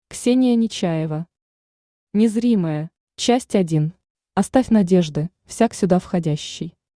Aудиокнига Незримое Автор Ксения Нечаева Читает аудиокнигу Авточтец ЛитРес.